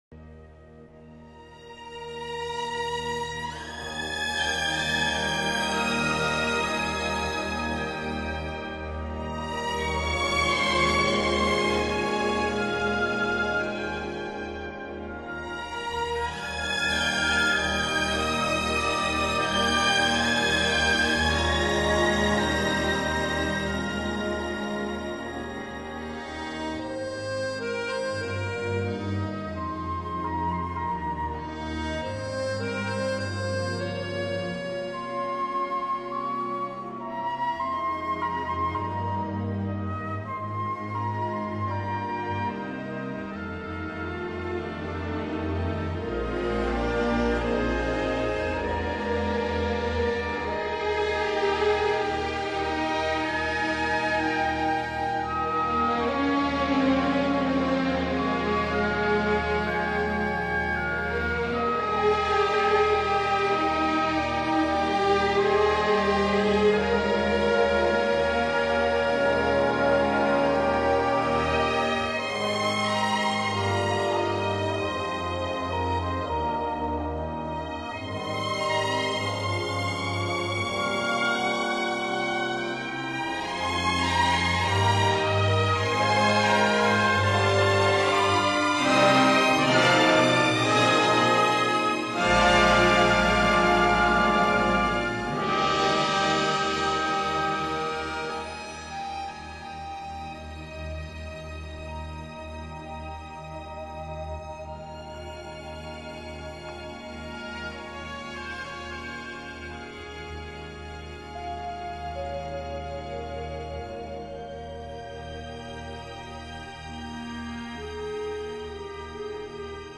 这个乐团的演奏风格流畅舒展，
旋律优美、动听，音响华丽丰满。
富有特色的弦乐演奏，就此成为他的乐团所独有的音乐特色。